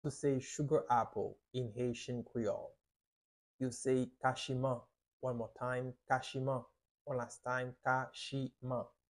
How to say "Sugar Apple" in Haitian Creole - "Kachiman" pronunciation by a native Haitian Teacher
“Kachiman” Pronunciation in Haitian Creole by a native Haitian can be heard in the audio here or in the video below:
How-to-say-Sugar-Apple-in-Haitian-Creole-Kachiman-pronunciation-by-a-native-Haitian-Teacher.mp3